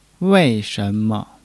wei4-shen2-me.mp3